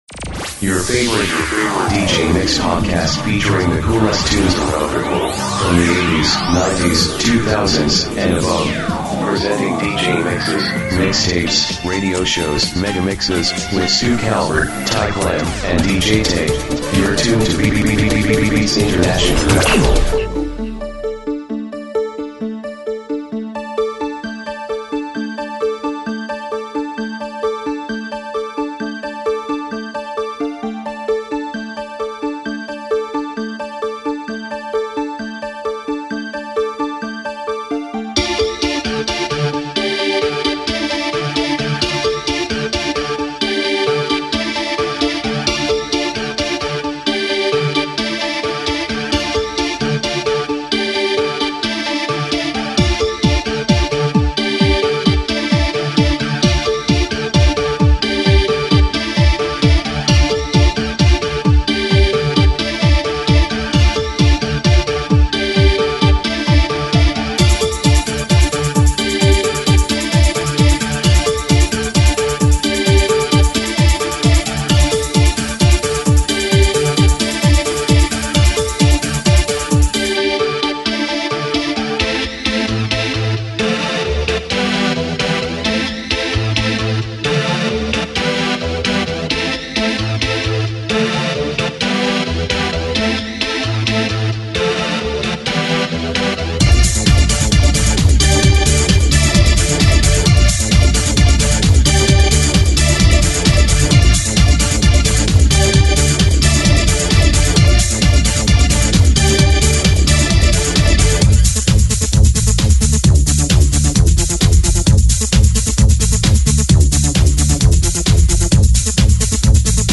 Part #7 of a 90’s Dance Mix series.